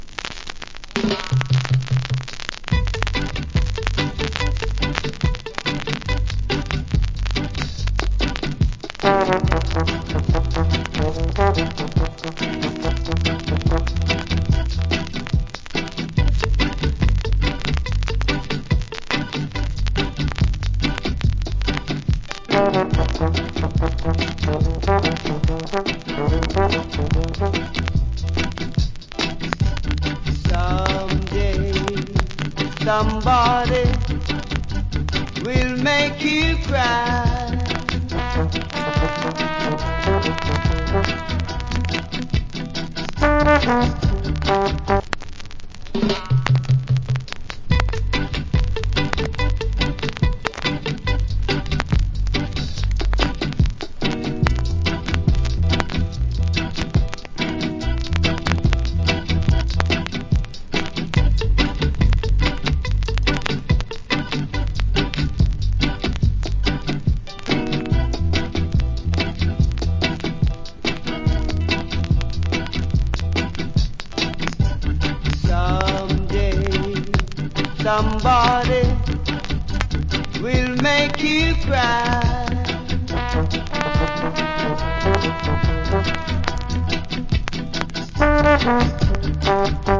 Wicked Inst.